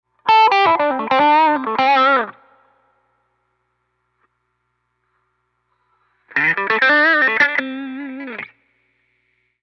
A Lick 75 Bpm Mono V10
描述：一段75bpm的单声道V10 粗糙的小strat音色，带有Wahwah。
Tag: 75 bpm Dirty Loops Guitar Electric Loops 826.98 KB wav Key : Unknown